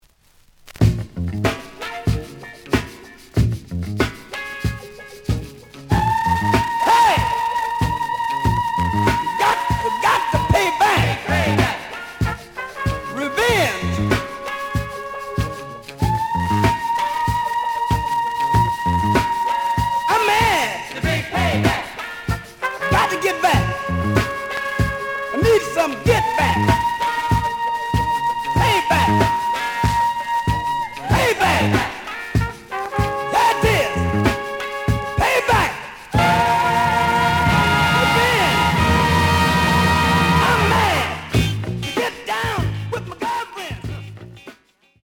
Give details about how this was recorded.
The audio sample is recorded from the actual item. Slight noise on both sides.